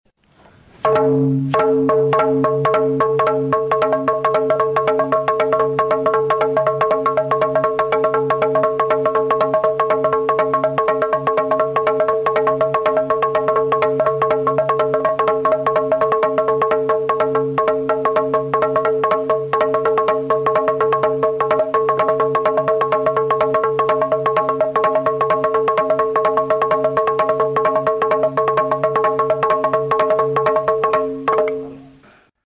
Instrumentos de músicaTxalaparta
Idiófonos -> Golpeados -> Directamente
txalaparta